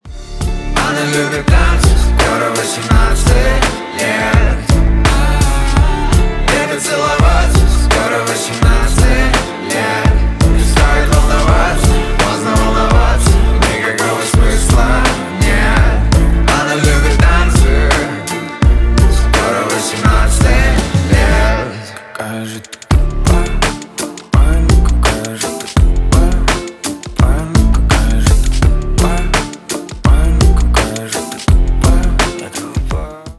Alternative
Рэп